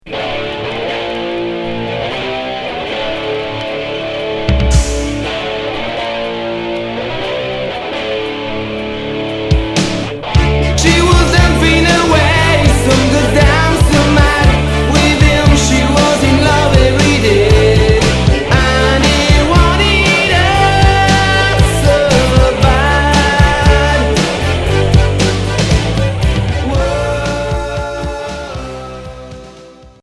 Hard FM Unique 45t retour à l'accueil